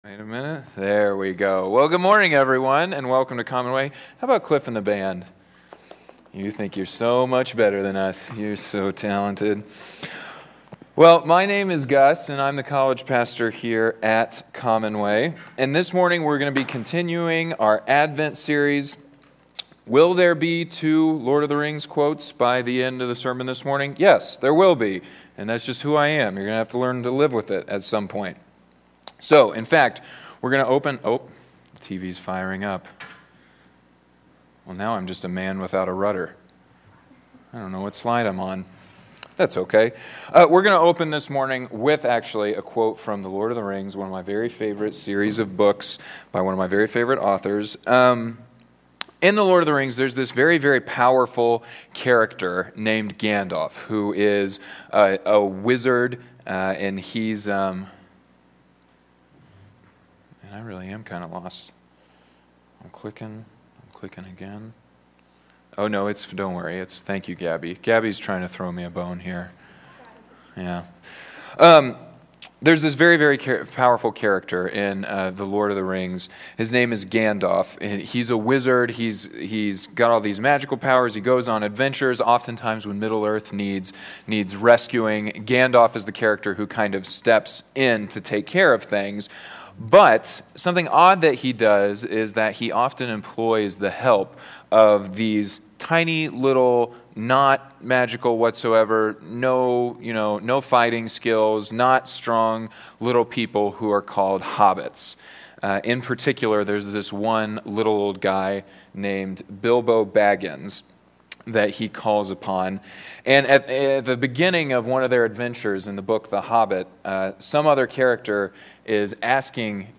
December-6-2020-Sermon-_-Commonway-Worship-Service.mp3